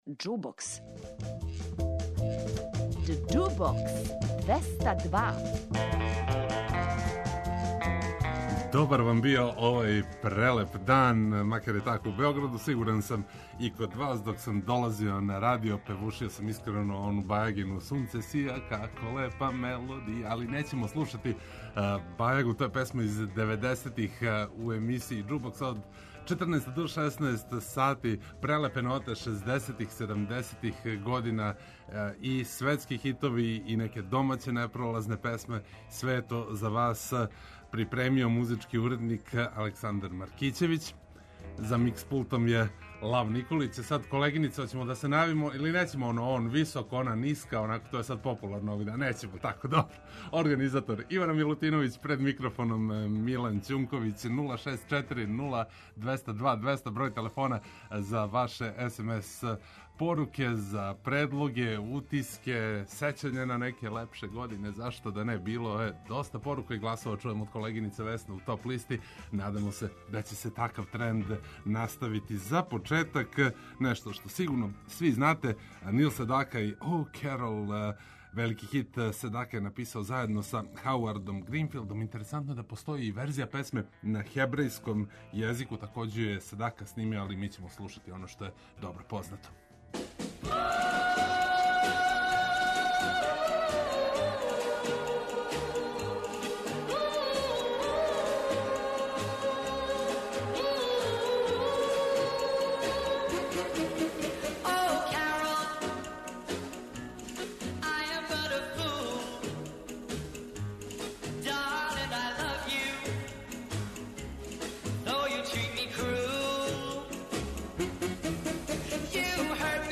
Пробране домаће и стране песме из шездесетих и седамдесетих учиниће још једно суботње поподне незаборавним.